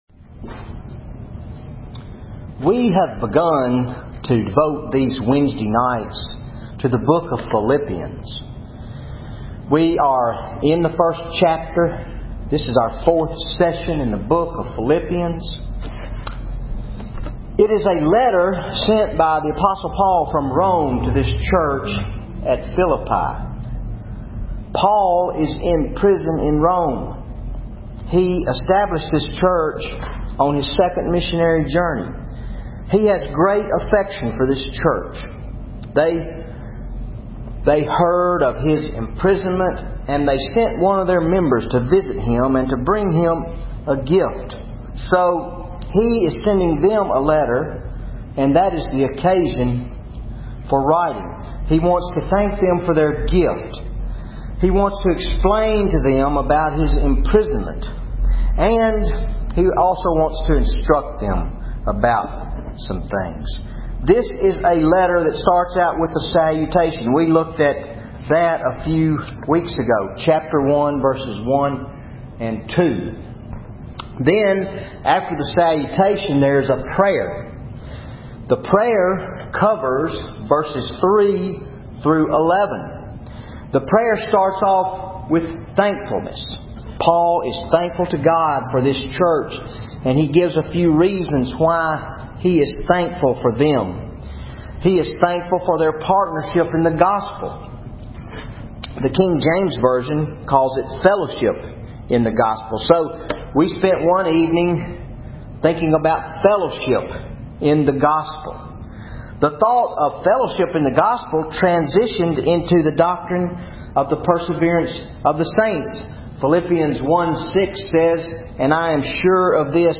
Wednesday Night Bible Study August 21, 2013 Philippians 1:9-11